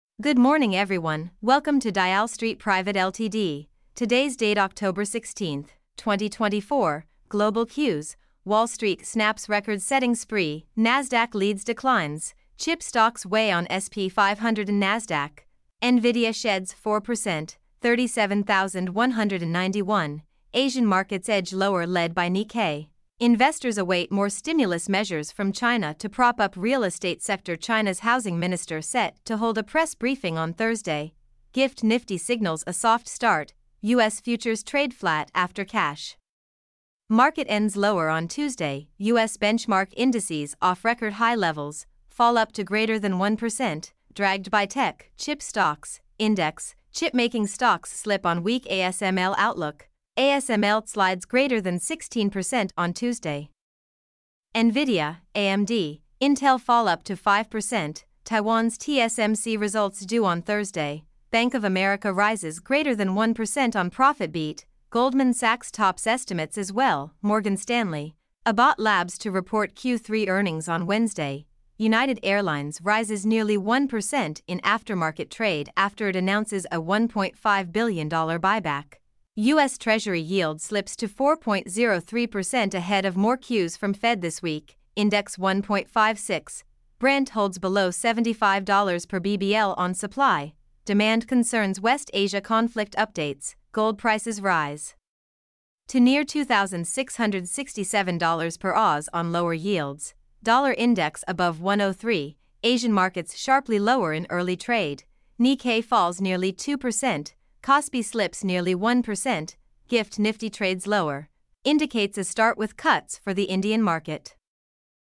mp3-output-ttsfreedotcom-9.mp3